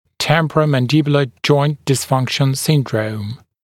[ˌtempərəmən’dɪbjulə ʤɔɪnt dɪs’fʌŋkʃn ‘sɪndrəum][ˌтэмпэрэмэн’дибйулэ джойнт дис’фанкшн ‘синдроум]синдром дисфункции височно-нижнечелюстного сустава